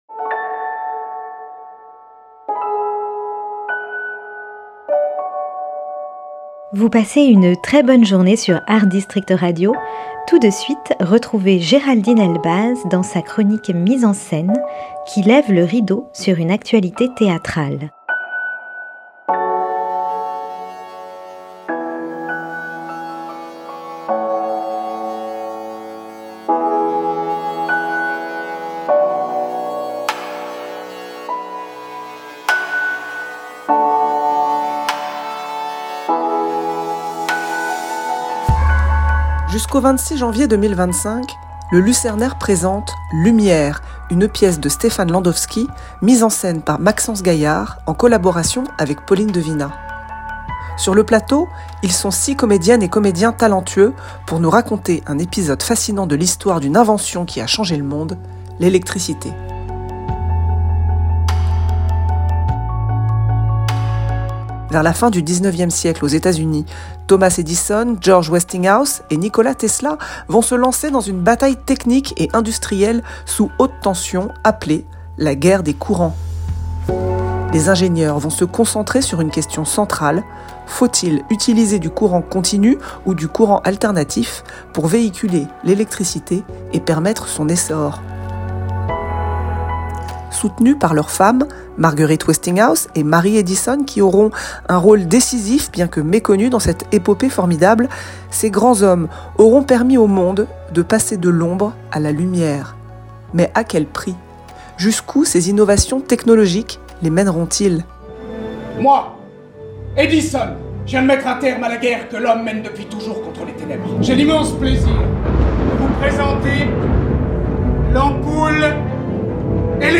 Chronique théâtrale